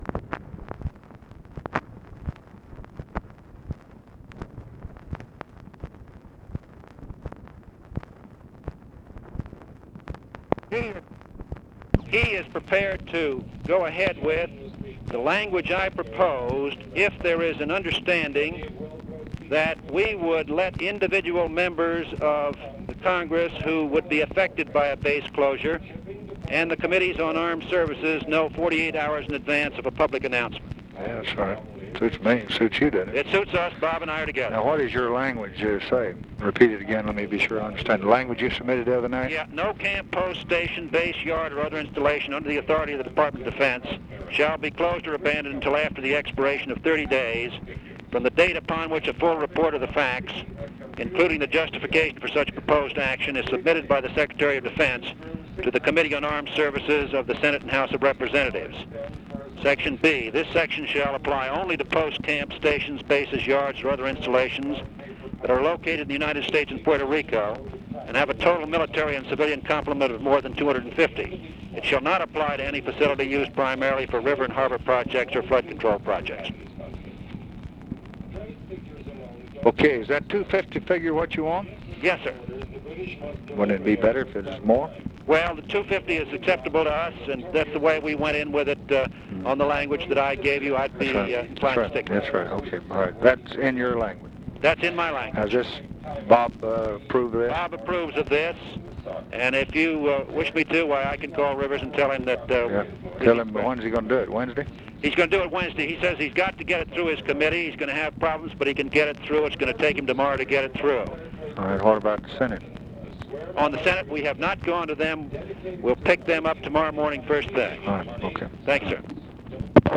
Conversation with CYRUS VANCE, August 23, 1965
Secret White House Tapes